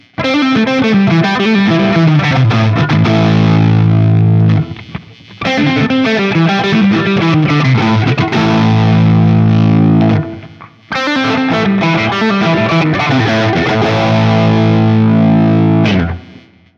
JCM-800
Riff
As usual, for these recordings I used my normal Axe-FX II XL+ setup through the QSC K12 speaker recorded direct into my Macbook Pro using Audacity.
This guitar has a very dark sound and the neck pickup especially turns to mud very easily if you just look at the tone knob.